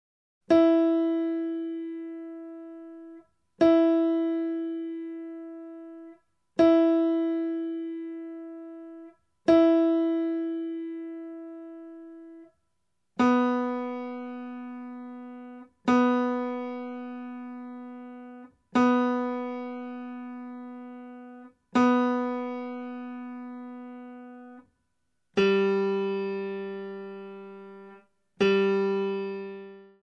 Voicing: Guitar w/r